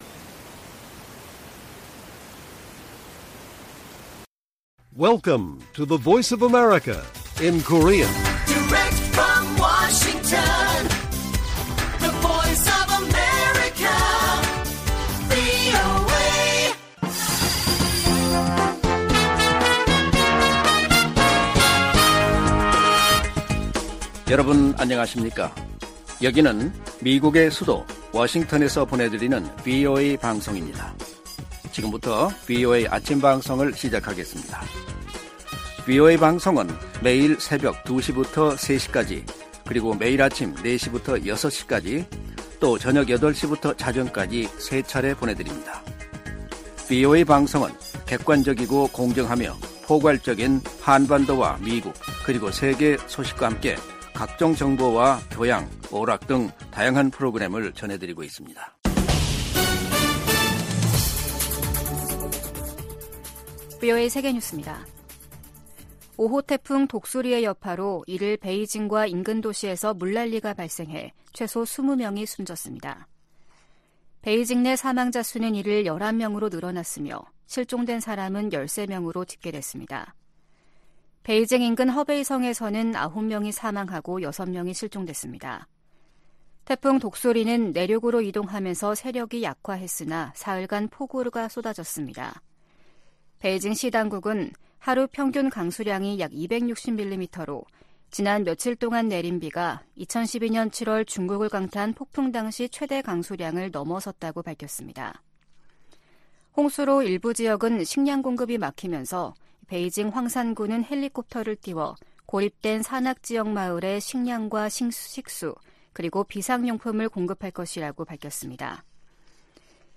세계 뉴스와 함께 미국의 모든 것을 소개하는 '생방송 여기는 워싱턴입니다', 2023년 8월 2일 아침 방송입니다. '지구촌 오늘'에서는 러시아 수도 모스크바가 다시 무인항공기(드론) 공격을 받은 소식 전해드리고, '아메리카 나우'에서는 조 바이든 대통령 차남 헌터 씨의 동료가 바이든 대통령과의 통화에서 사업 거래에 관한 이야기는 없었다고 증언한 이야기 살펴보겠습니다.